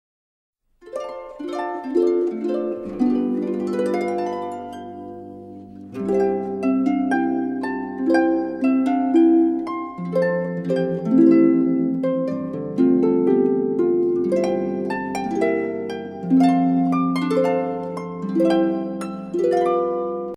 ハープ
ハープ.mp3